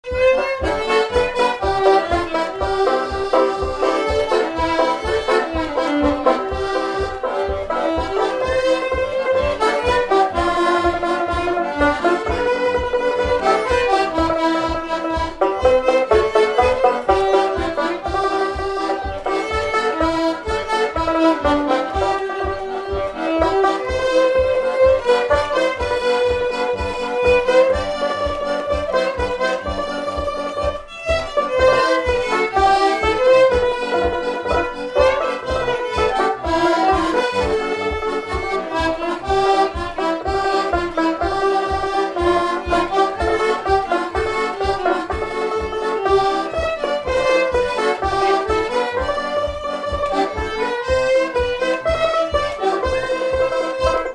Instrumental
Pièce musicale inédite